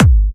VEC3 Bassdrums Trance 24.wav